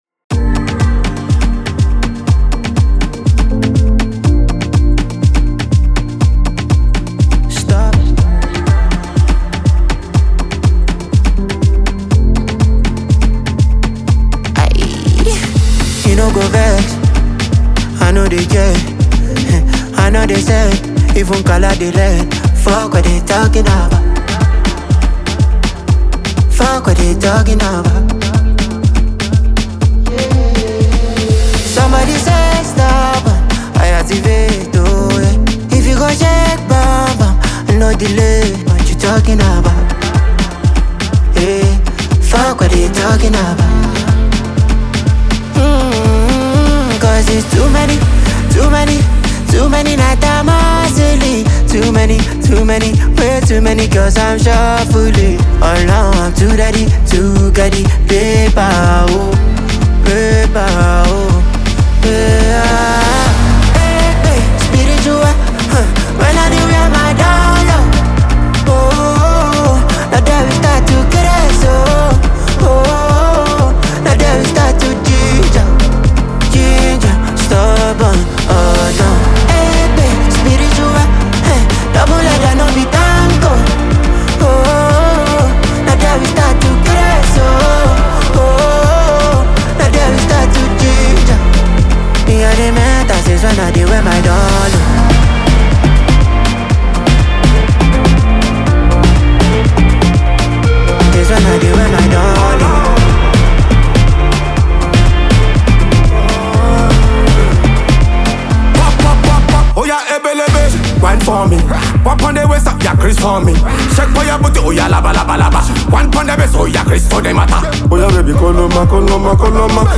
street-hop
smooth vocals and infectious melodies